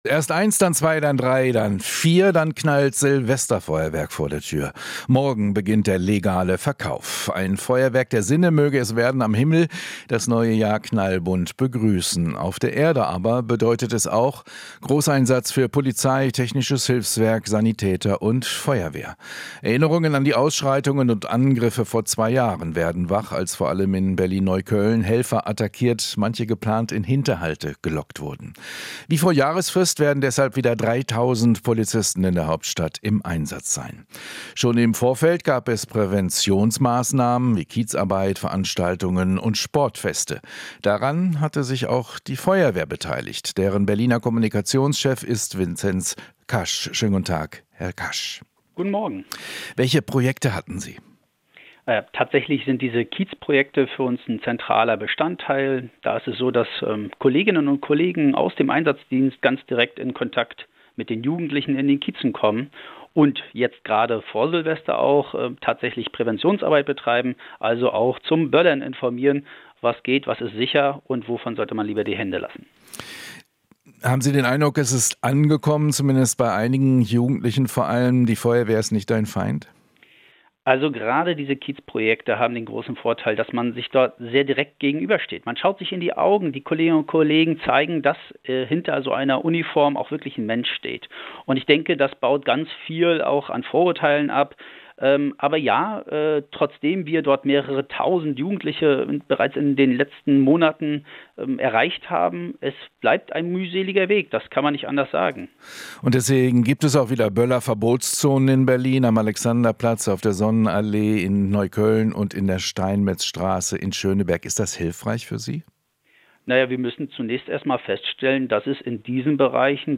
Interview - Wie sich die Berliner Feuerwehr auf Silvester vorbereitet